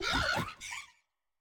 Cri de Flamenroule dans Pokémon Écarlate et Violet.